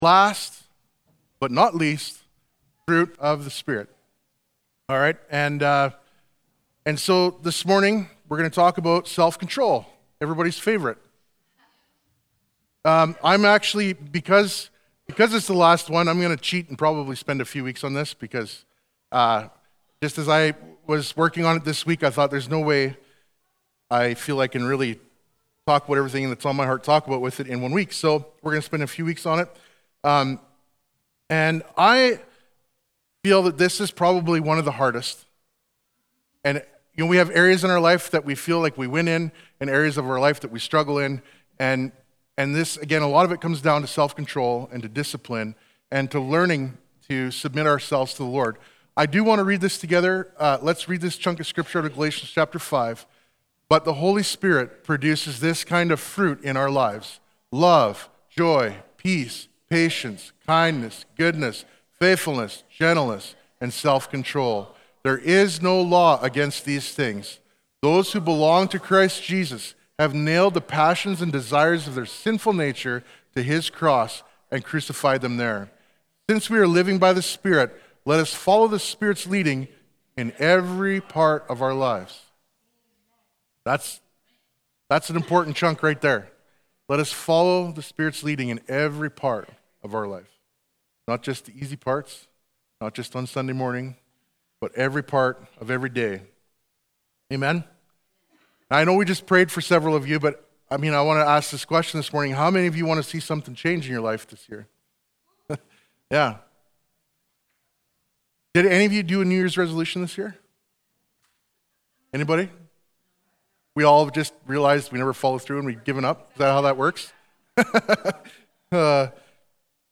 Sermons | Family Church in Maple Creek